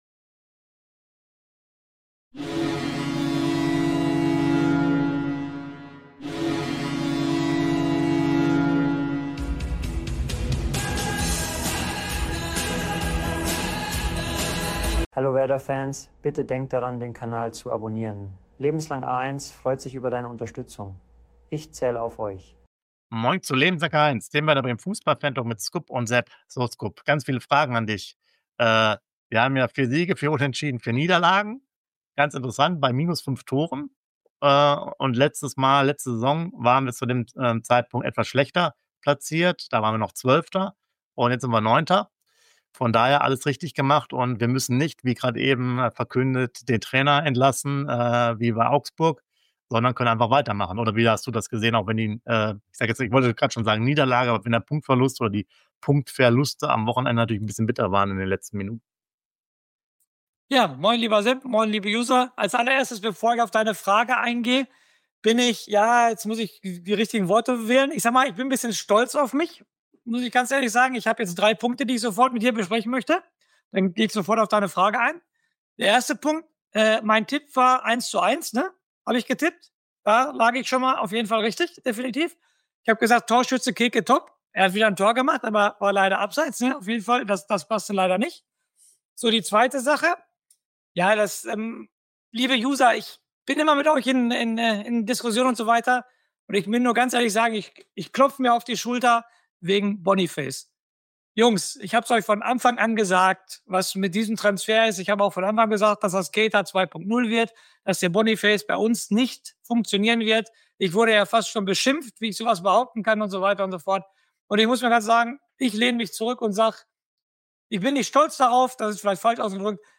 Werder Bremen vs. FC Erzgebirge Aue – Impressionen/Reportage aus dem Stadion